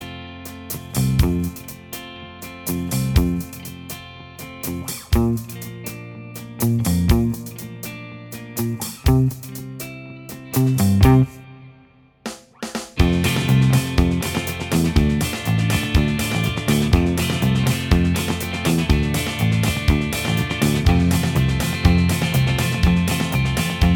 Minus Guitars Pop (1960s) 2:28 Buy £1.50